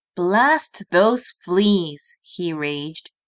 英語の朗読ファイル